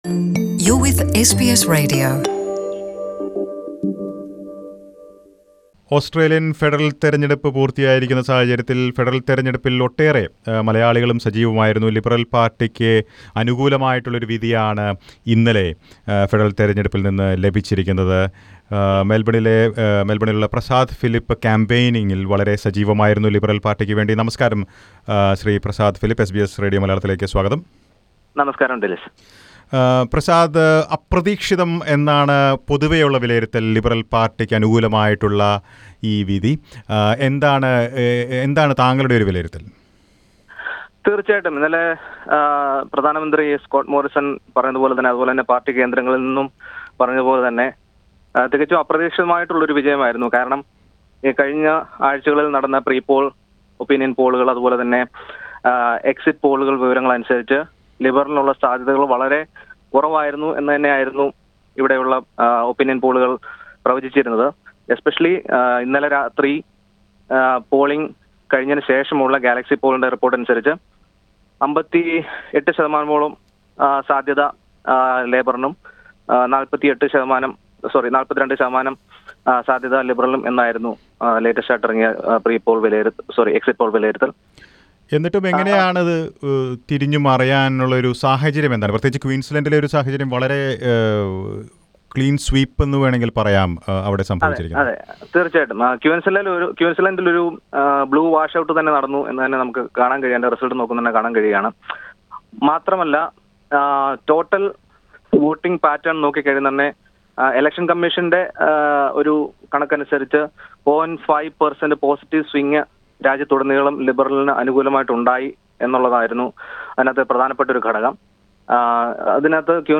Liberal party's fortunes were better on election day and Scott Morrison remains Australia's prime minister. What went right for the Liberal party on election day. An analysis.